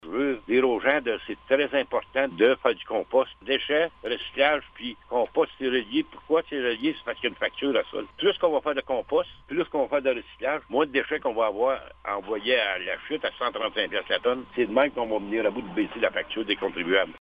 Par cette décision, la municipalité veut encourager les citoyens à adhérer au compostage. Écoutons les propos du maire de Blue Sea, Laurent Fortin :